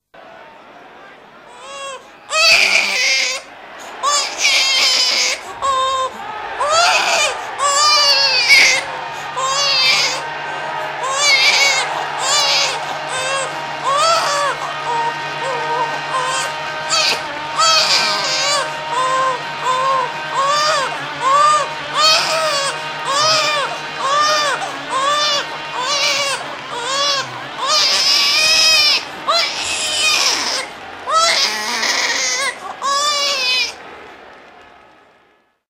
Oei, luister maar, de baby huilt.
baby huilt, mixer.mp3 (838.9 KB)